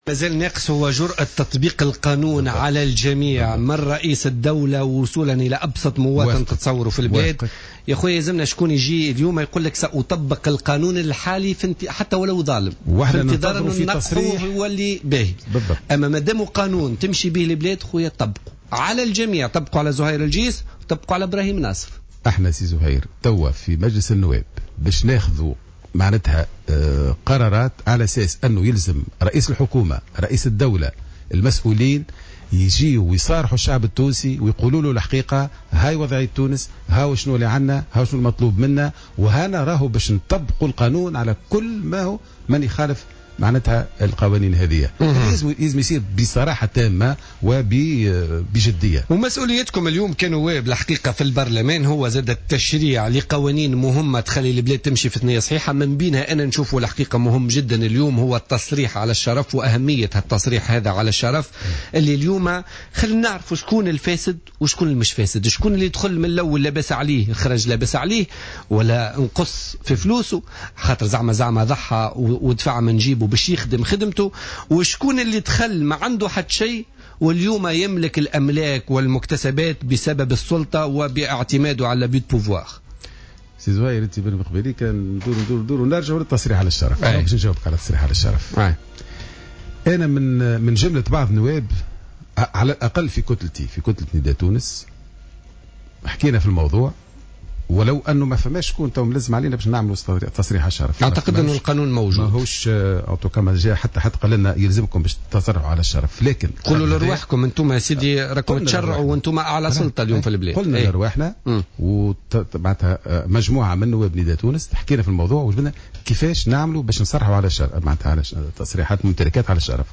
قال النائب عن نداء تونس بمجلس نواب الشعب ابراهيم ناصف ضيف بوليتيكا اليوم الخميس...